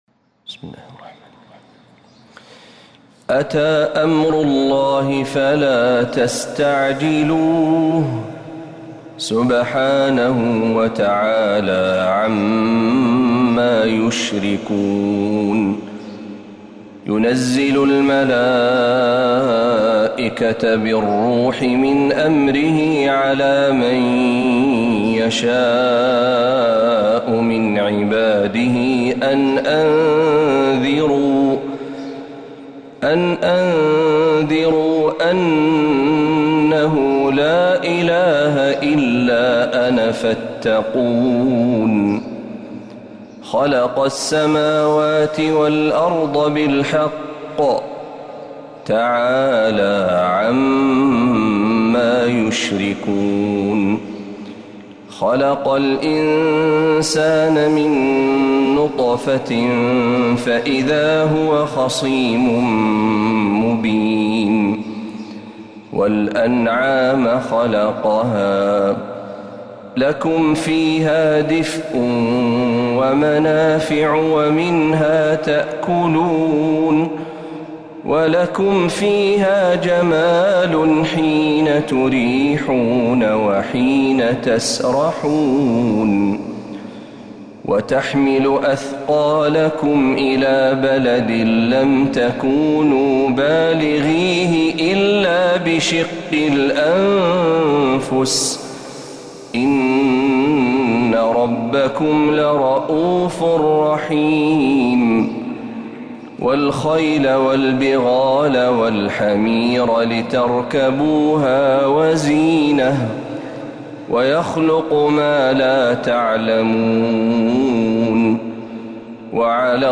سورة النحل كاملة من الحرم النبوي